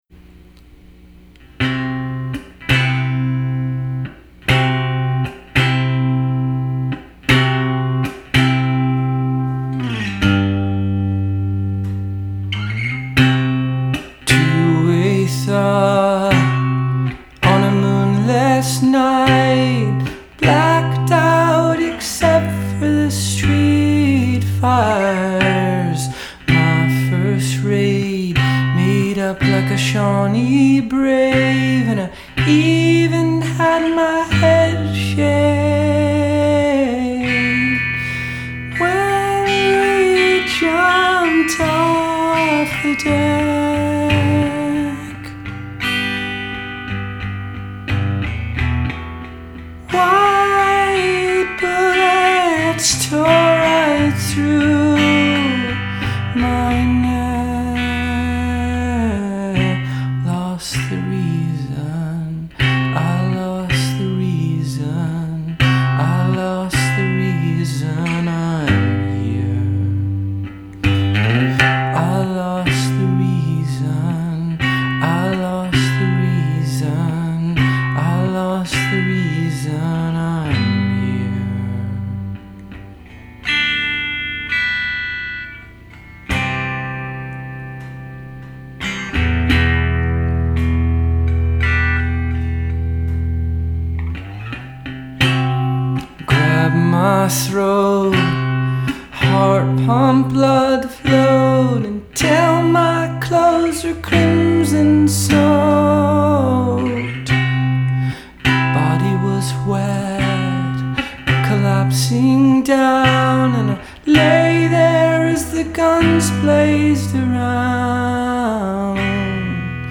Genre: indie rock / pop